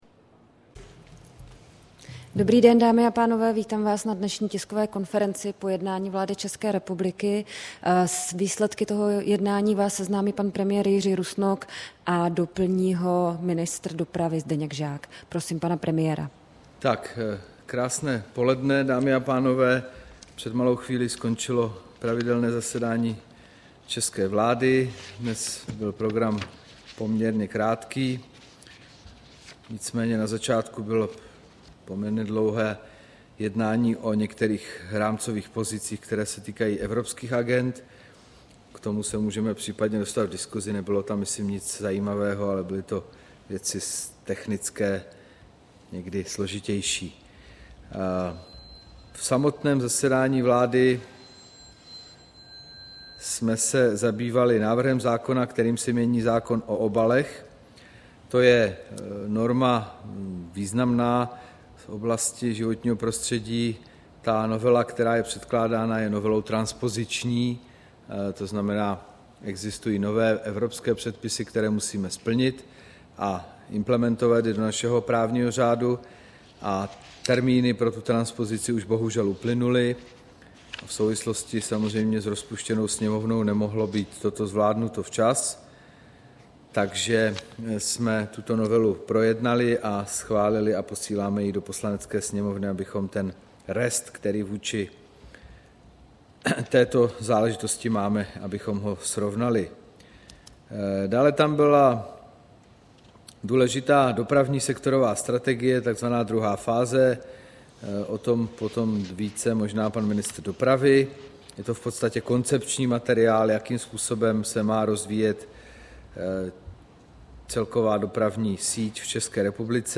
Tisková konference po jednání vlády, 13. listopadu 2013